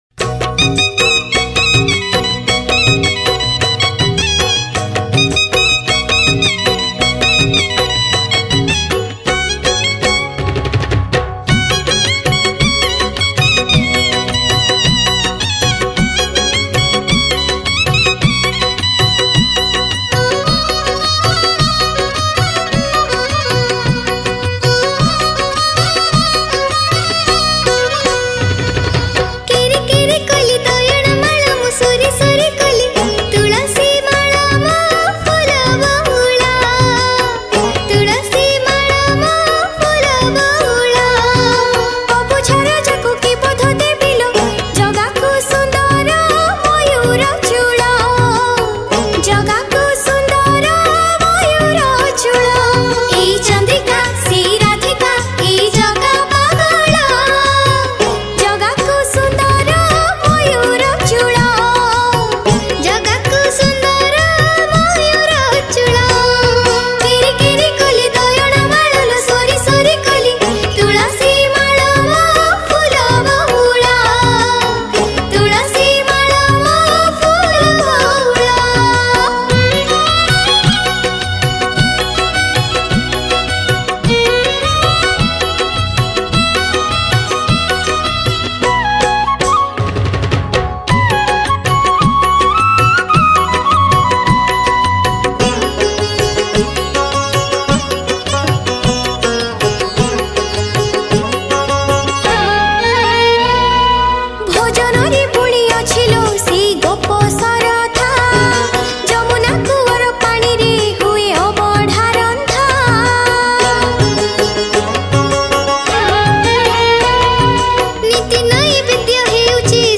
Odia Bhajan Song 2022 Songs Download